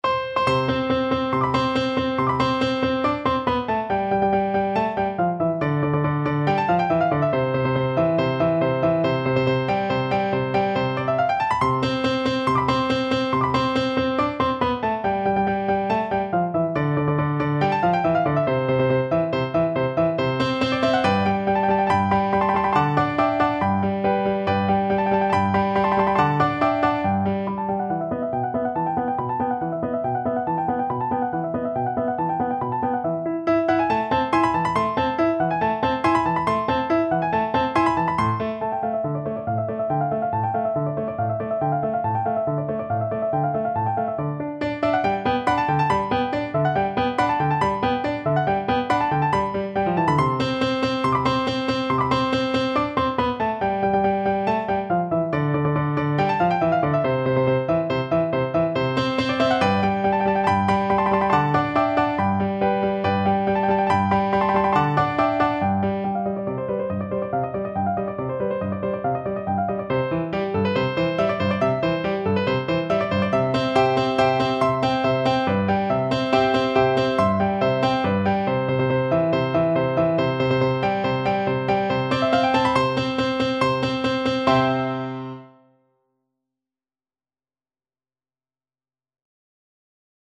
Play (or use space bar on your keyboard) Pause Music Playalong - Piano Accompaniment Playalong Band Accompaniment not yet available transpose reset tempo print settings full screen
C major (Sounding Pitch) (View more C major Music for Violin )
~ = 140 Allegro vivace (View more music marked Allegro)
Classical (View more Classical Violin Music)